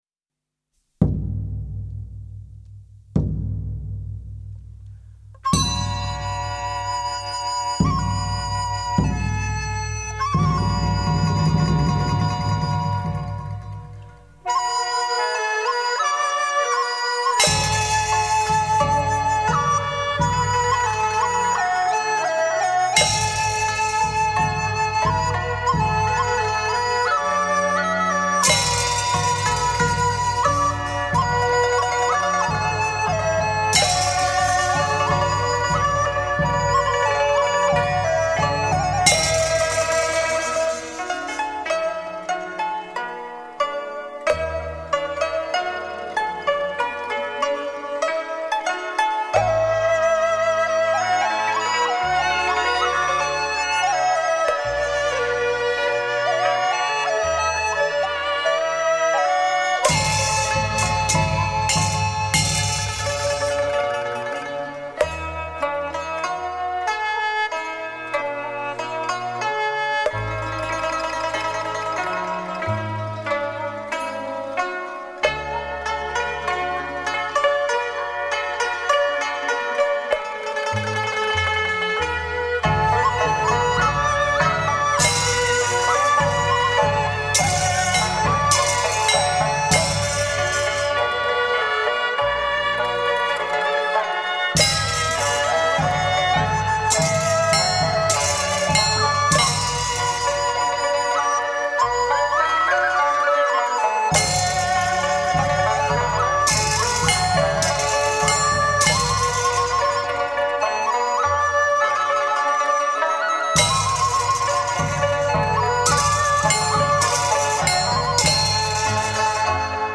艺术家: 道教音乐
音乐类型: 民乐
充分表现出道家韵腔的况味。